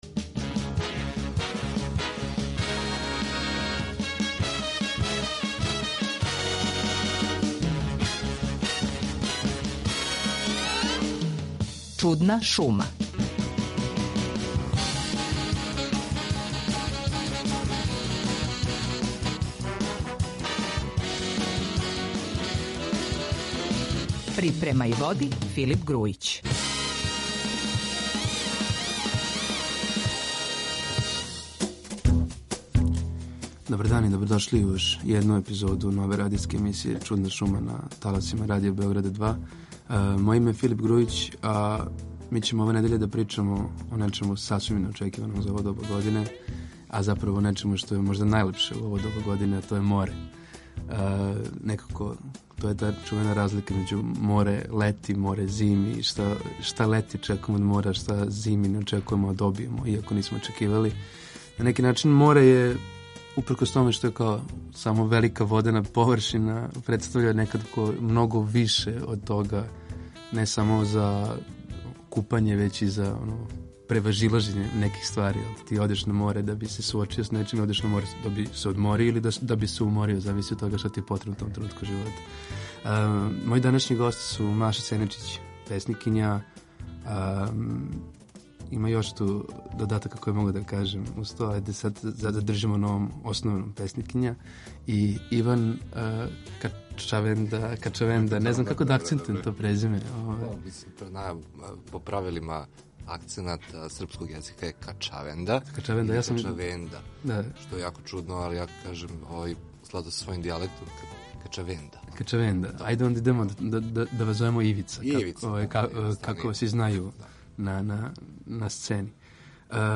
У новој епизоди емисије Чудна шума на таласима Радио Београда 2 разговараћемо о мору